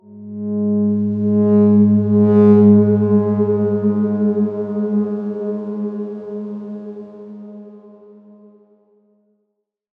X_Darkswarm-G#2-pp.wav